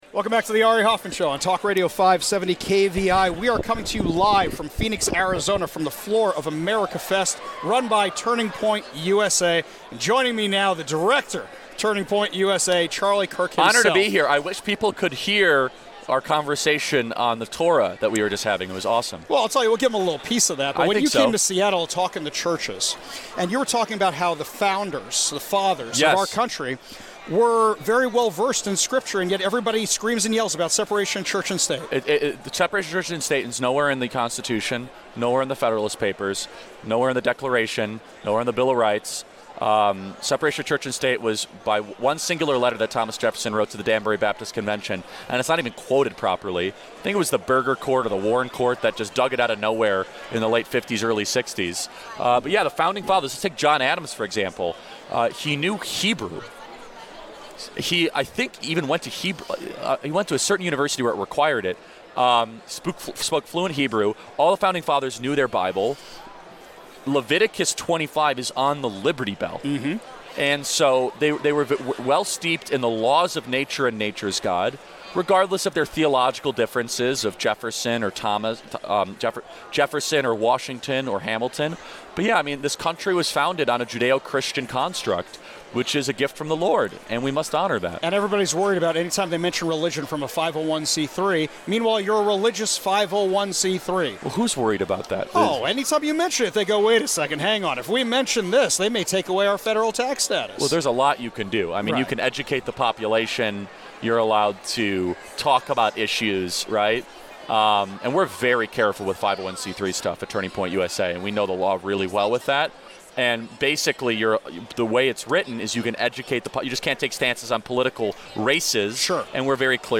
My first time broadcasting from Turning Point USA’s AmericaFest in Phoenix, Arizona, in December 2021, I was fortunate enough to interview Charlie Kirk.
A Shared Faith Conversation